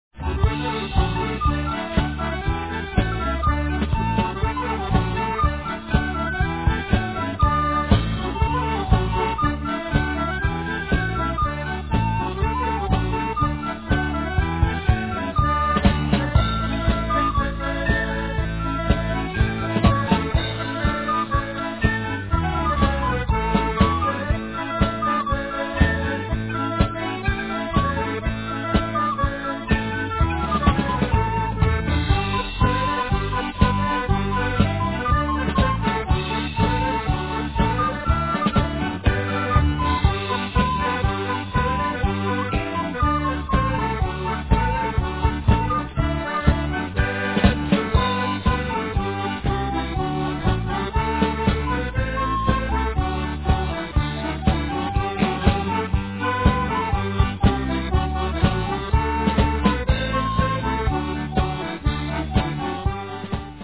7x32 Reels